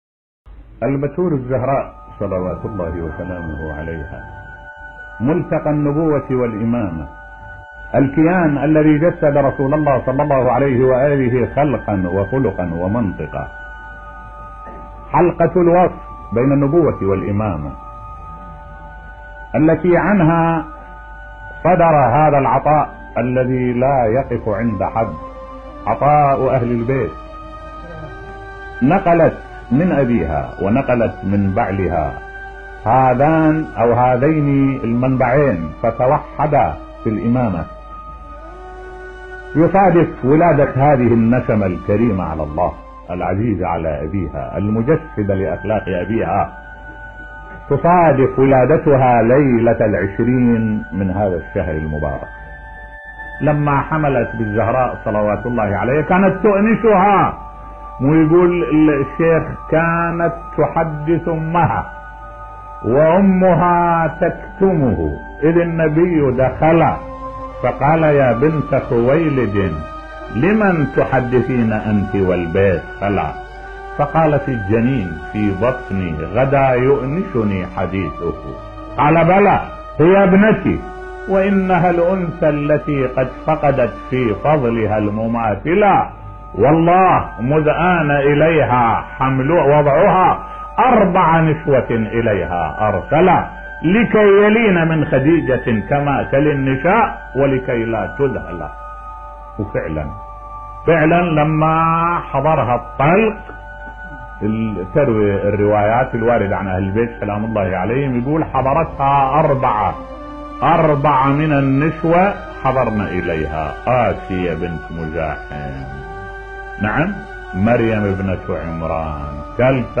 محاضرات - الكوثر: الشيخ الوائلي يتحدث عن ولادة فاطمة الزهراء سلام الله عليها.